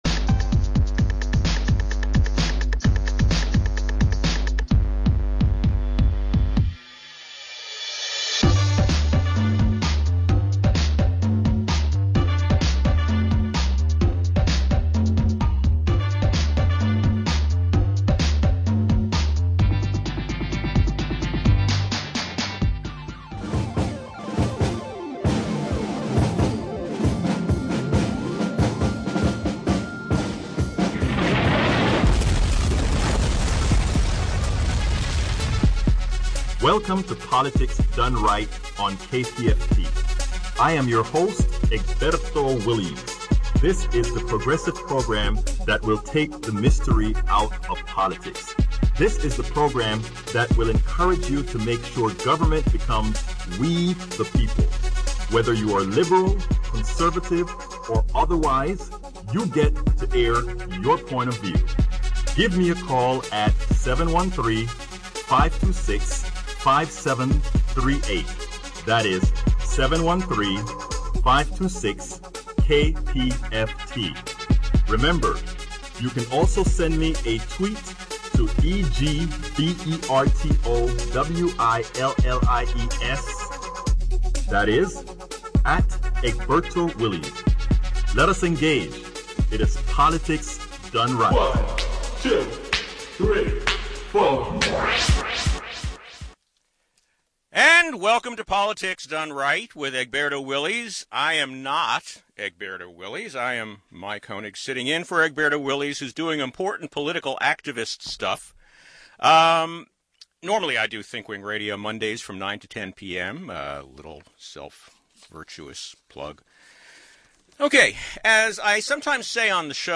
a listener call-in show